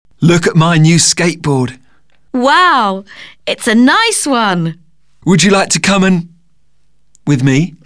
Dans le dialogue, un mot n'est pas audible.